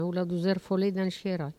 Patois
Catégorie Locution